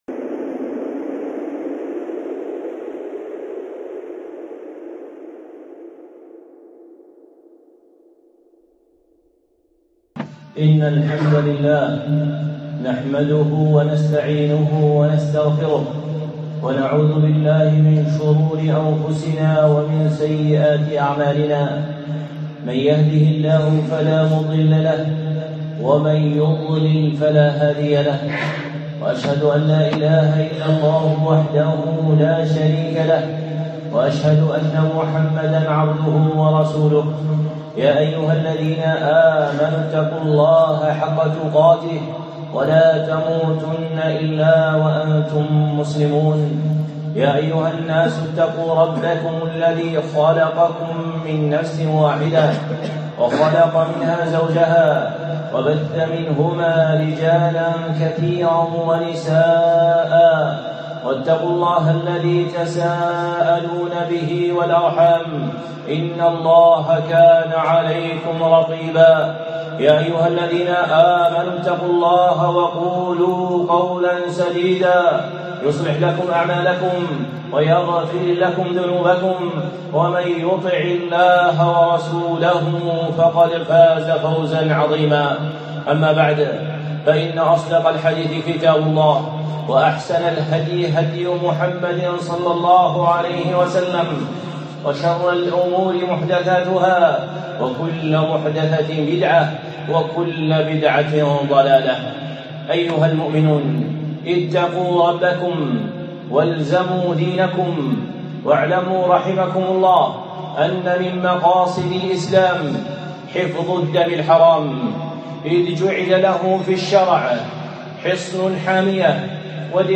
خطبة (حرمة الدماء)